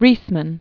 (rēsmən), David, Jr. 1909-2002.